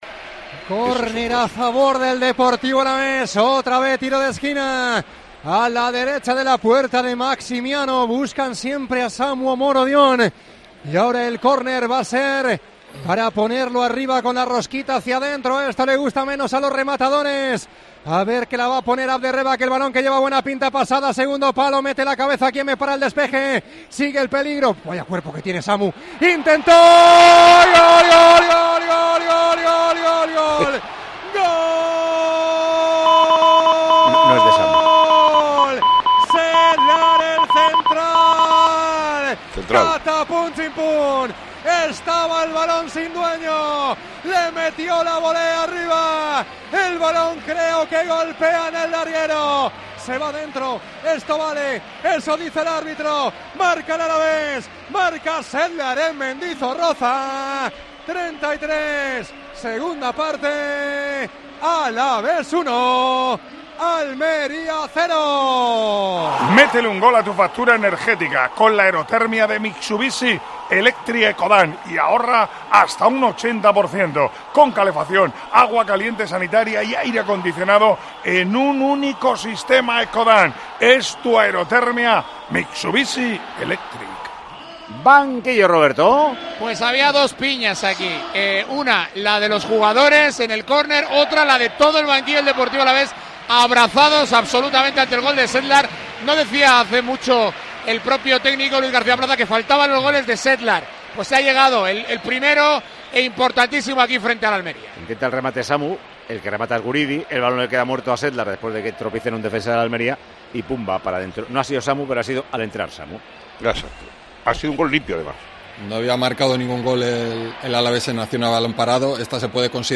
COPE, en Mendizorroza